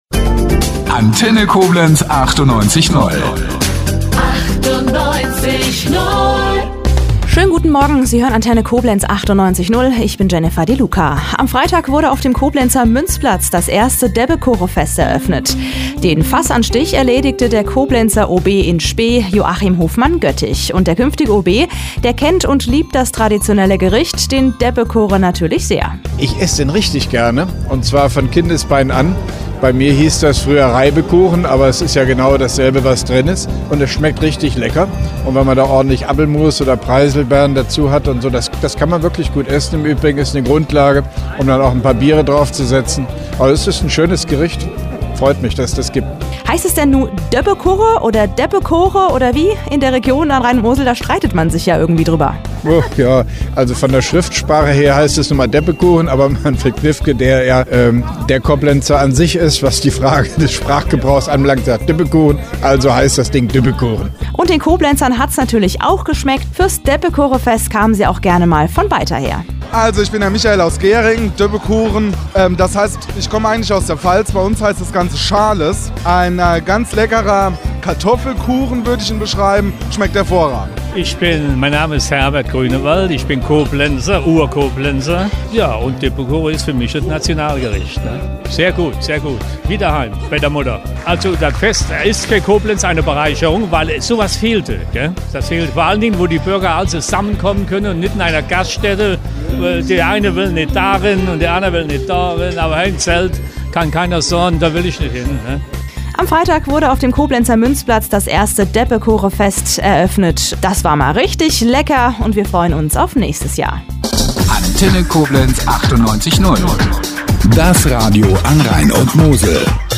Interview von Joachim Hofmann-Göttig beim ersten Koblenzer Debbekoochenfest am Freitag 06.11.2009